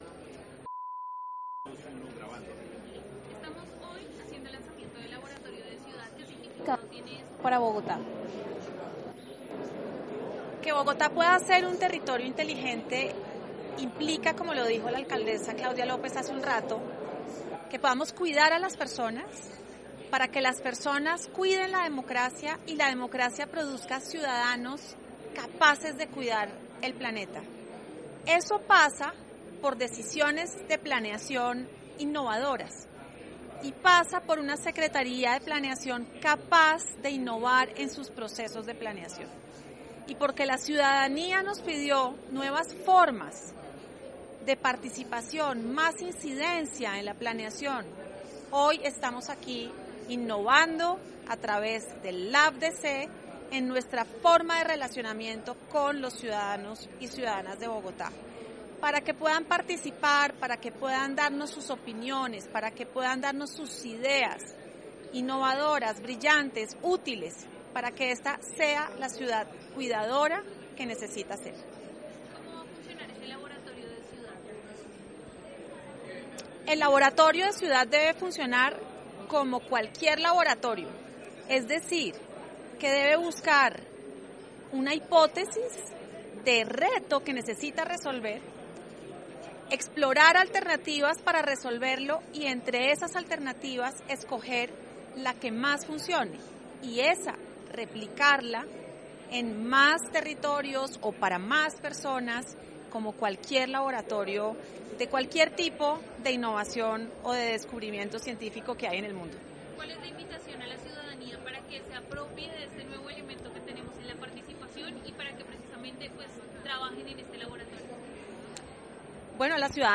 Declaraciones secretaria de Planeación, María Mercedes Jaramillo.